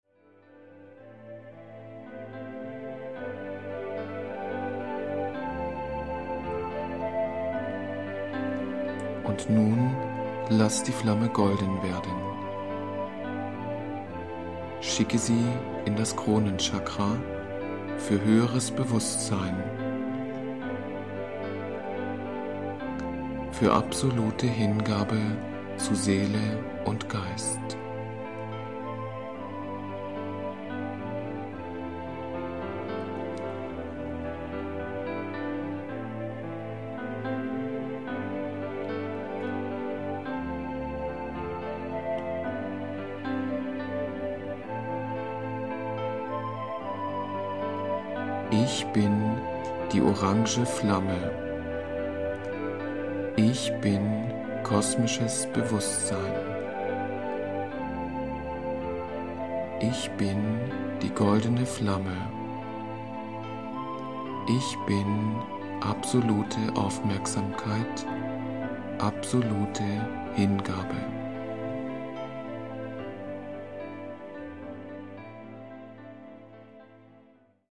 Die CD 2 enthält folgende Meditationen: